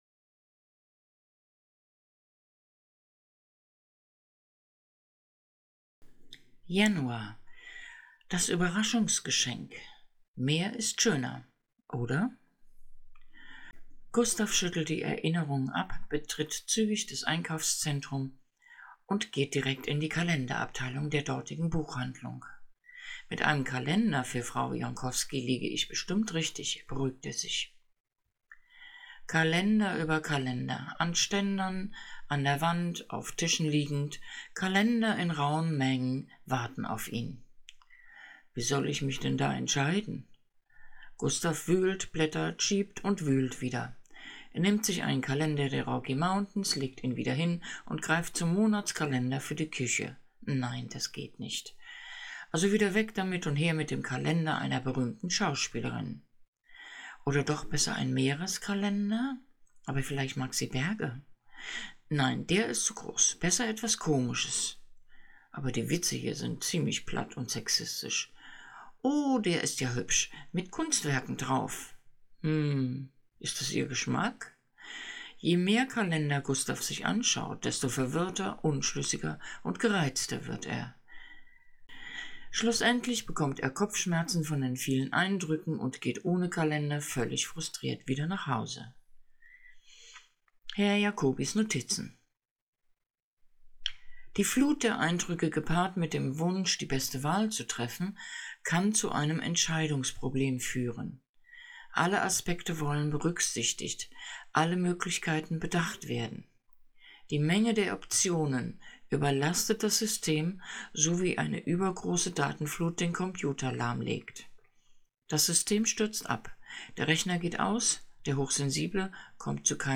Hörprobe "Ein hochsensibles Jahr mit Gustav" -Erste Episoden